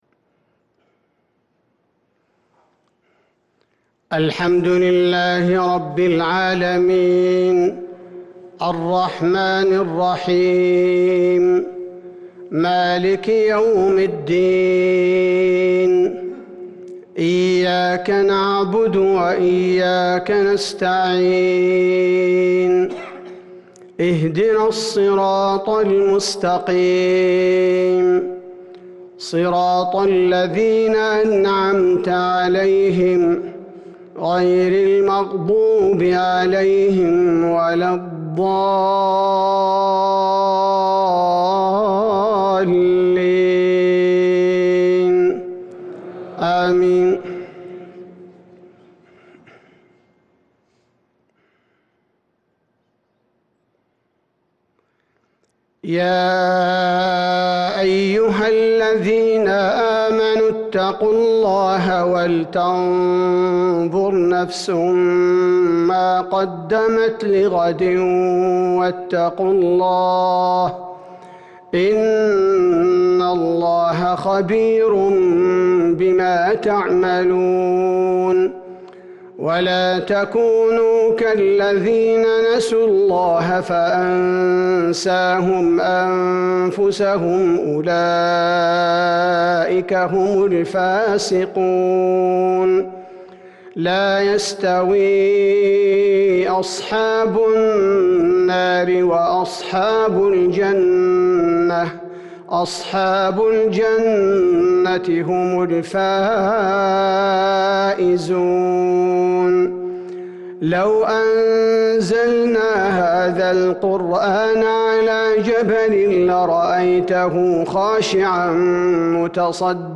عشاء ٢٩ جمادى الأخرة ١٤٤٣هـ سورة الحشر | Isha prayer from Surah al-Hash 1-2-2022 > 1443 🕌 > الفروض - تلاوات الحرمين